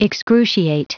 Prononciation du mot excruciate en anglais (fichier audio)